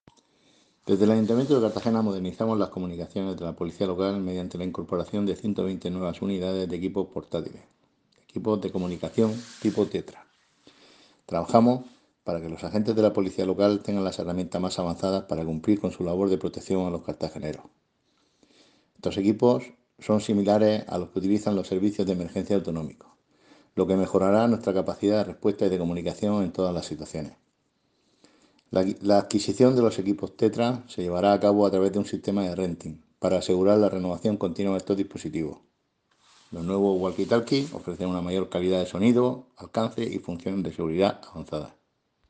Enlace a Declaraciones del edil José Ramón Llorca.